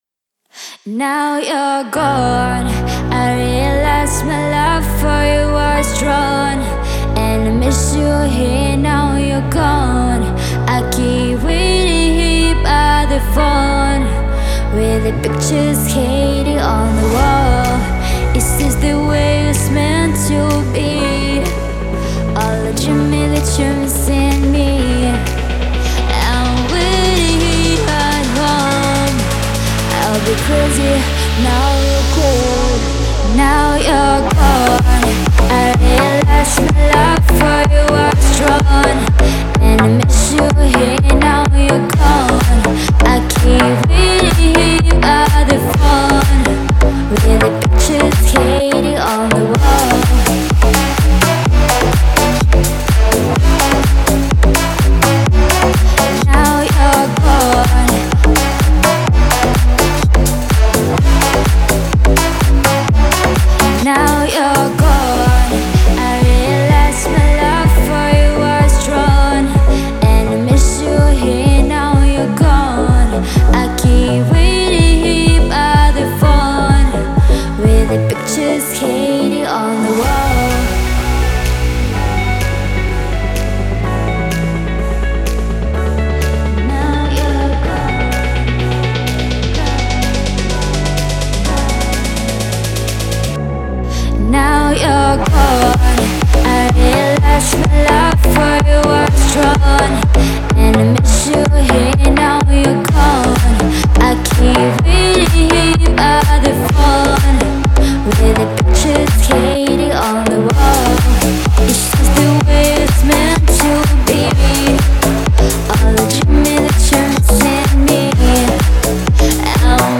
это динамичная трек в жанре электронной танцевальной музыки